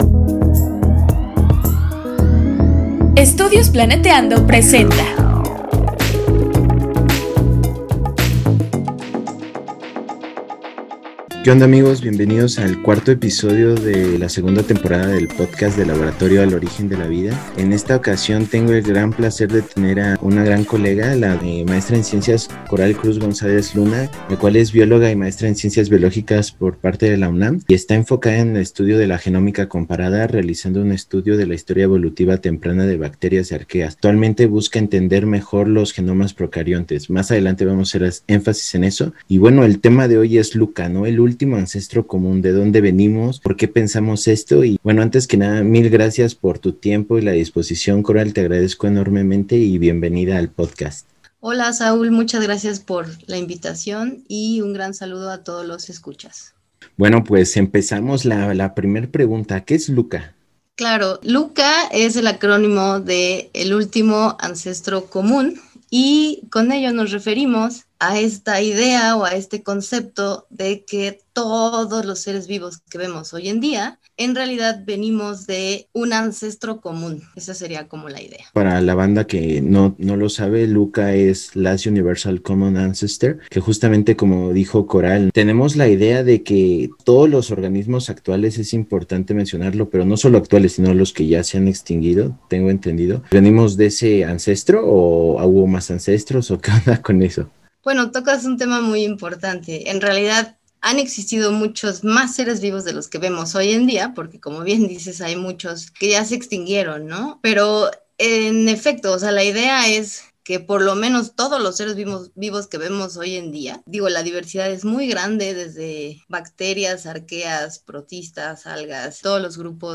¿De dónde venimos y cuándo existió nuestro antepasado en común? Descubre cómo pudo ser el organismo del cual descendió toda la vida que conocemos. Entrevista